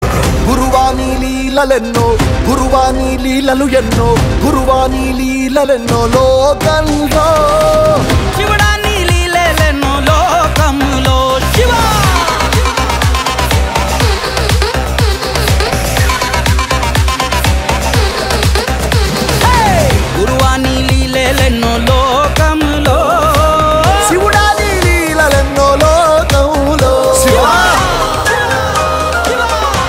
a captivating melody